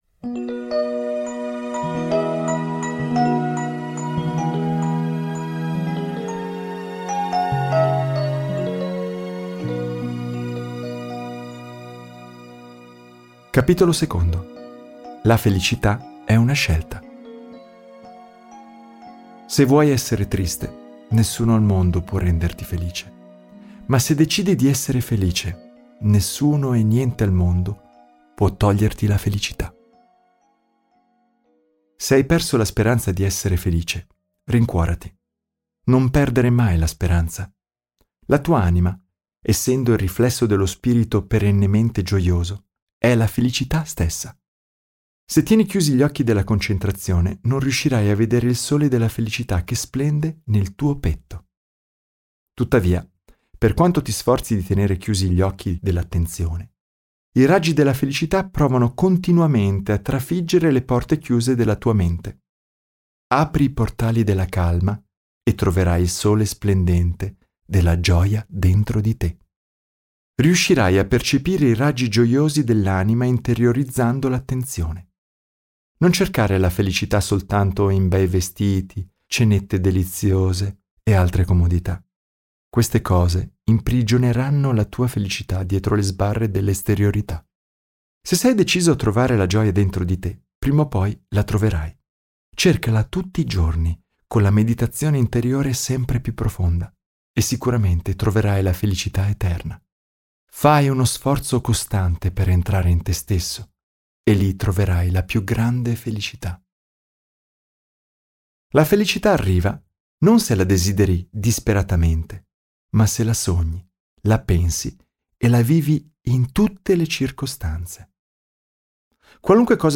Registrato presso il Jyoti Studio di Ananda Assisi nel 2019.
estratto-come-essere-sempre-felici-audiolibro.mp3